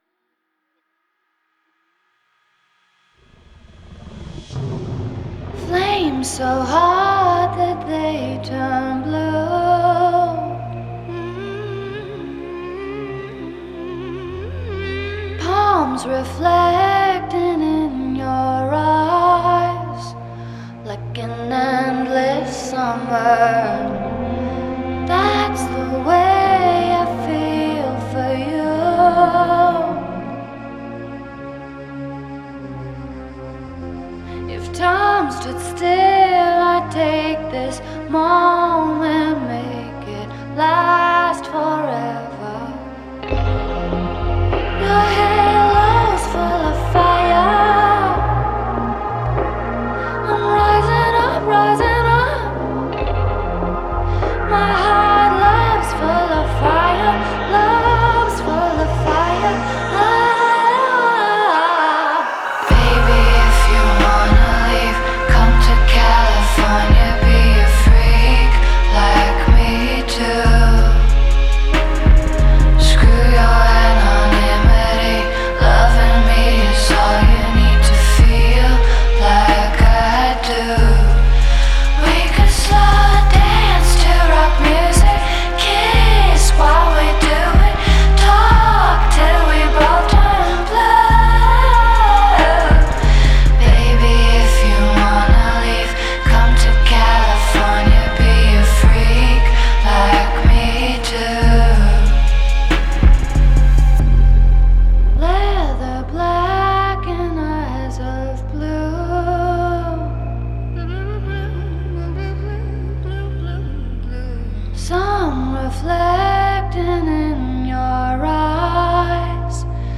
Genre: Pop, Singer-Songwriter